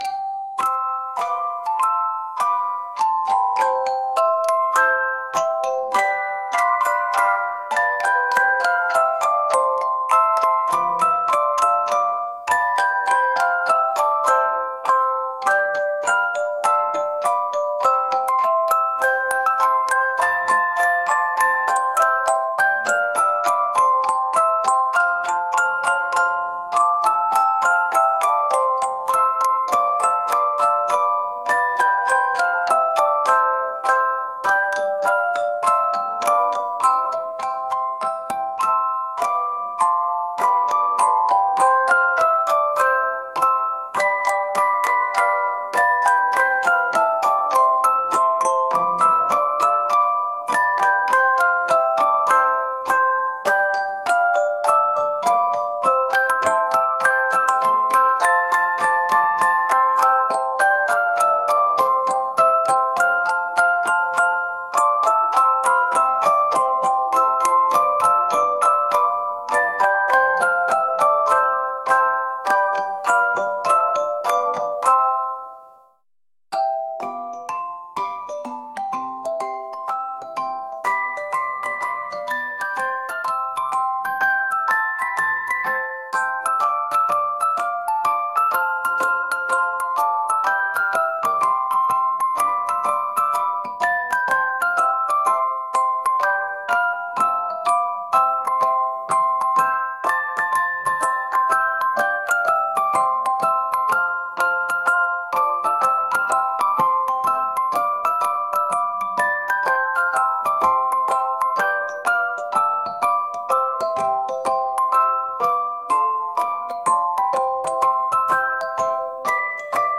オルゴール